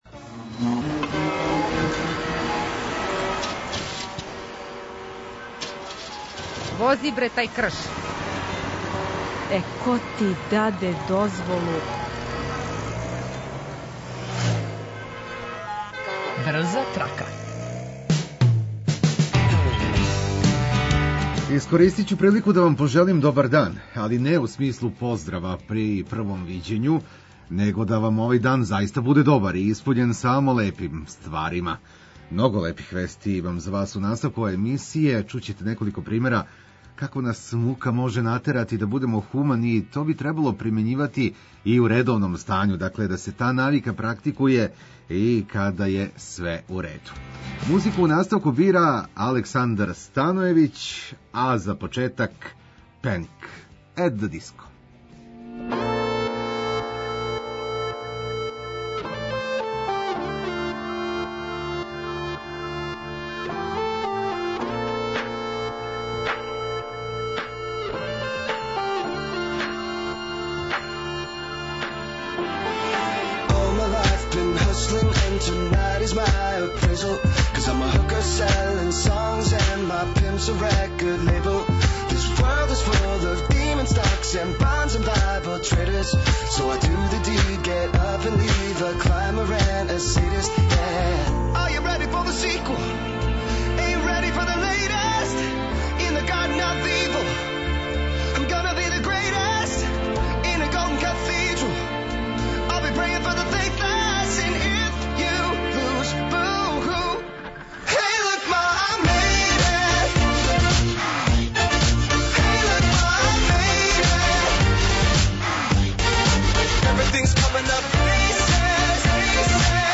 Tu je i odlična muzika koja će vam olakšati svaki minut, bilo gde da se nalazite.